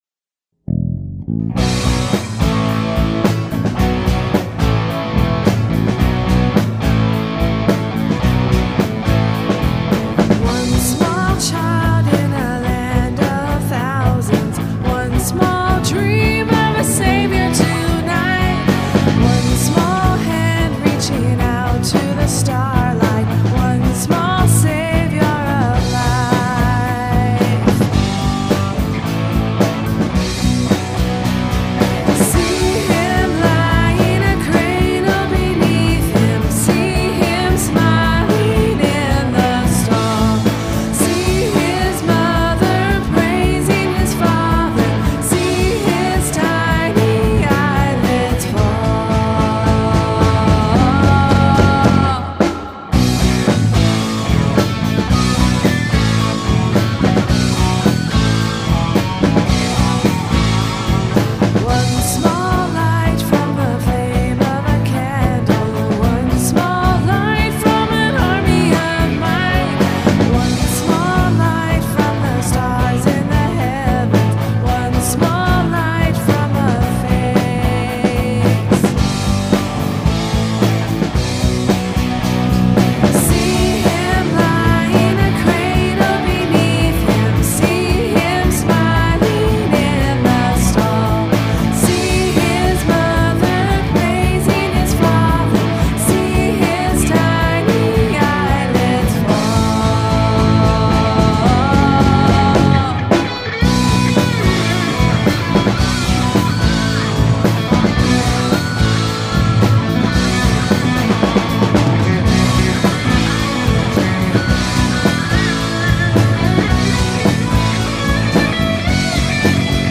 recorded live
Vocals / Acoustic Guitar
Bass
Electric Guitar
Drums
Piano
Flute
Viola